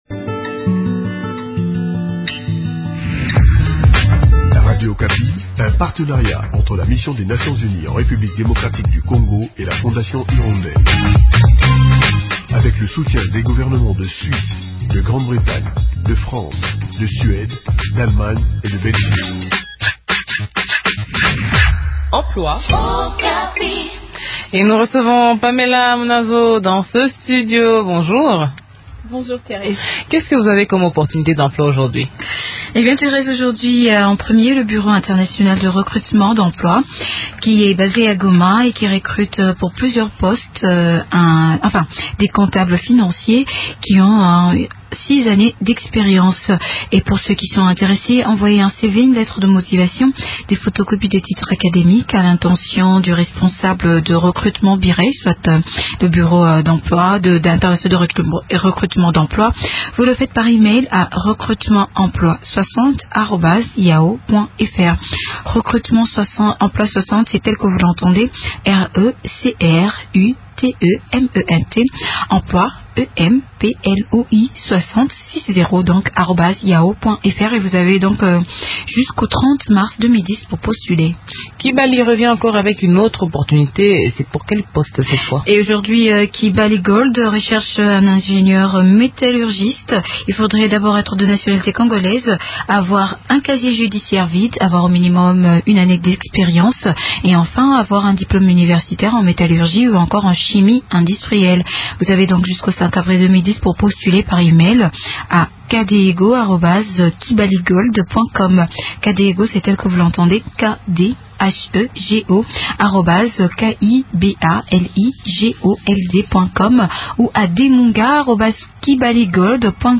Cependant dans ces villages, la situation humanitaire reste toujours préoccupante. Suivez l’état des lieux dans cet entretien